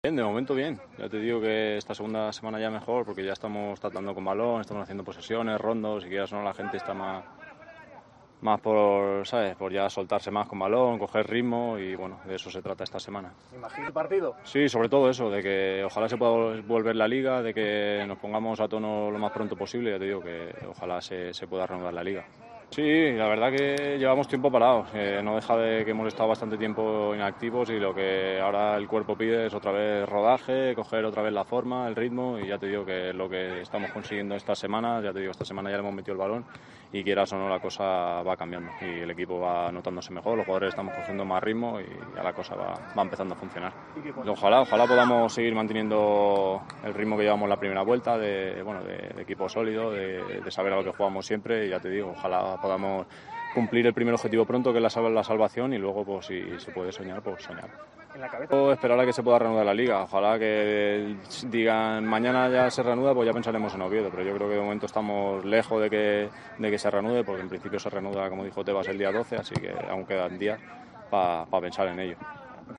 Escucha aquí las palabras del centrocampista de la Deportiva Ponferradina